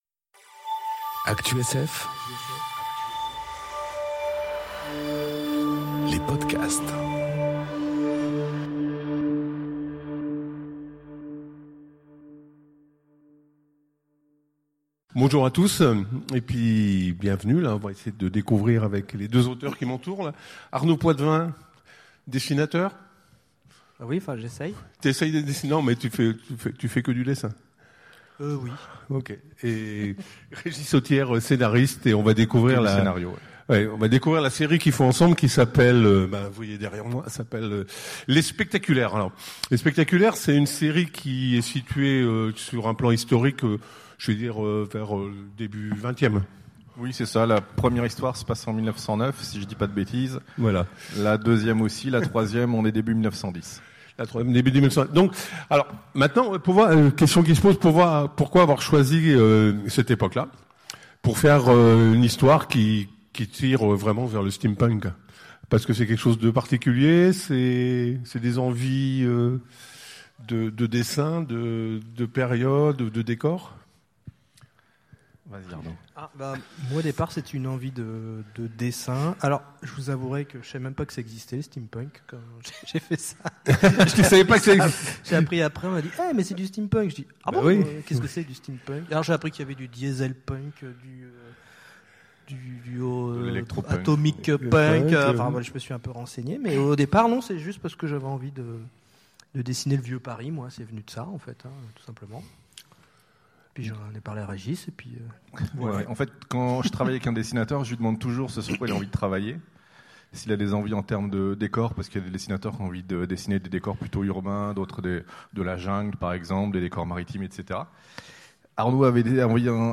Conférence Autour des Spectaculaires enregistrée aux Utopiales 2018